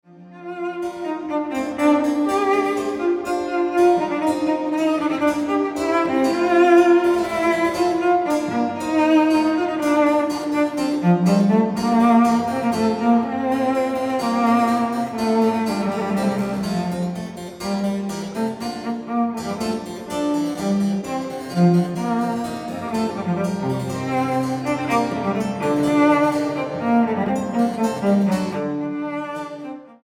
violoncelo